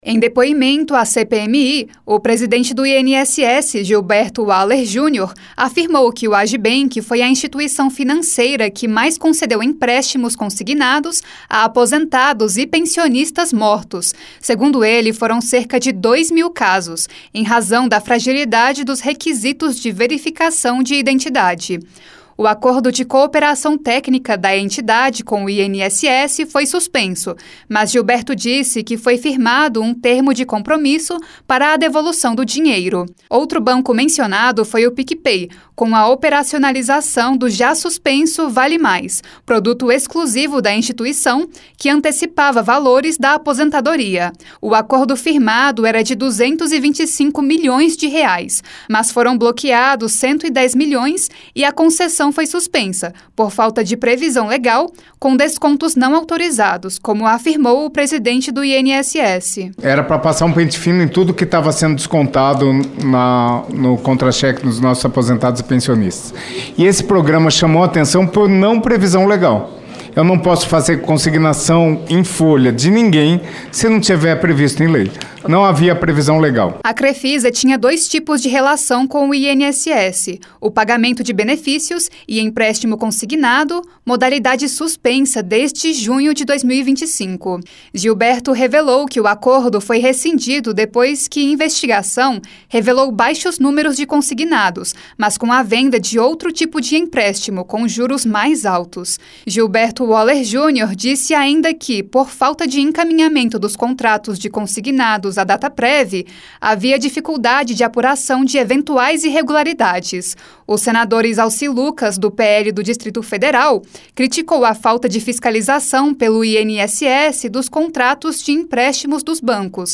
Durante depoimento nesta quinta-feira (5), o presidente do INSS, Gilberto Waller Júnior, afirmou que o AgiBank foi a instituição financeira que mais concedeu empréstimos consignados a aposentados e pensionistas que já estavam mortos — segundo ele, foram constatados cerca de 2 mil empréstimos nessa situação. A audiência, que ainda não acabou, é promovida pela CPMI do INSS.